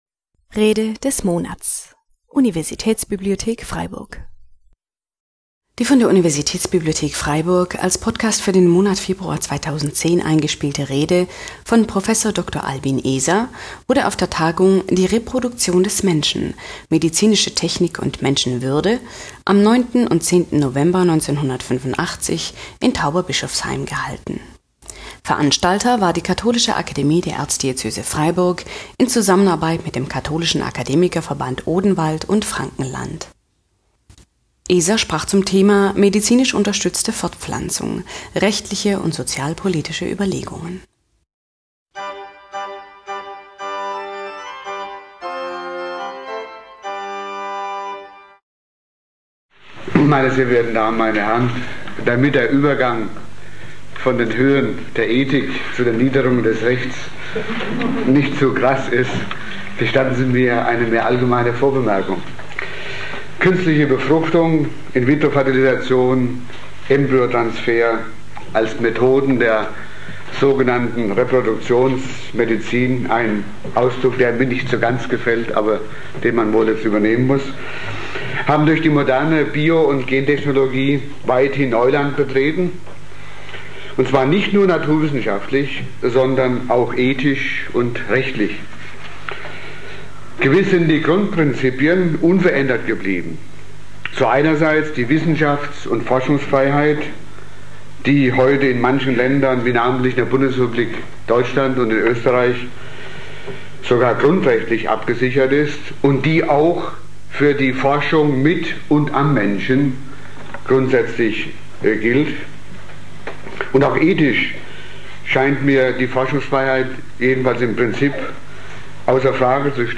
Medizinisch unterstützte Fortpflanzung – Rechtliche und sozialpolitische Überlegungen (1985) - Rede des Monats - Religion und Theologie - Religion und Theologie - Kategorien - Videoportal Universität Freiburg